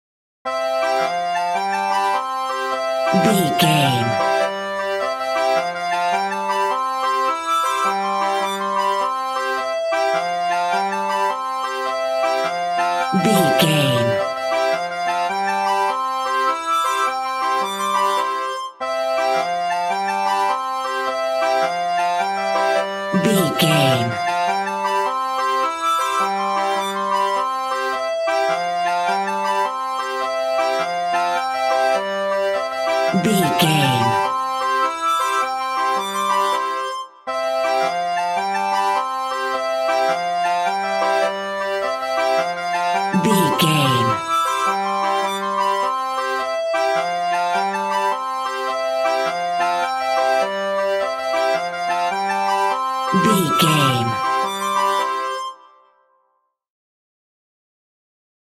Ionian/Major
Fast
nursery rhymes
childrens music